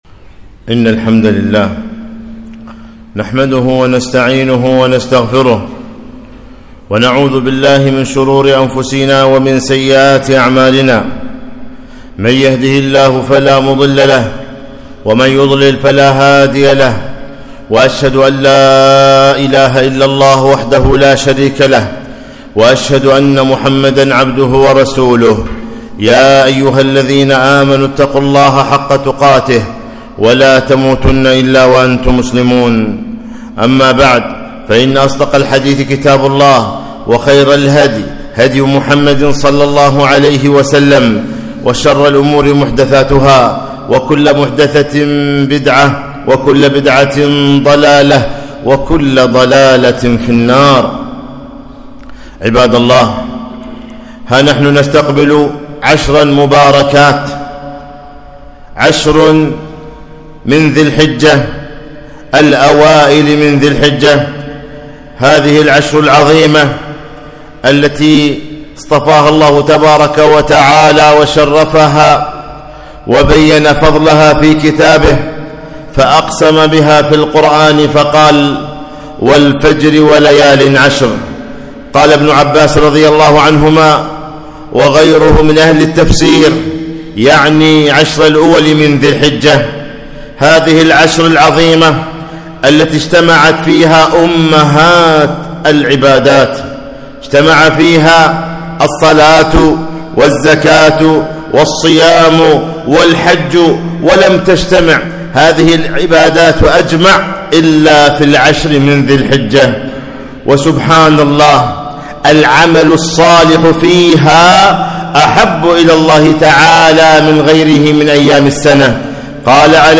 خطبة - خير أيام الدنيا